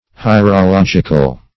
Search Result for " hierological" : The Collaborative International Dictionary of English v.0.48: Hierologic \Hi`er*o*log"ic\, Hierological \Hi`er*o*log"ic*al\, a. [Cf. F. hi['e]rologique.]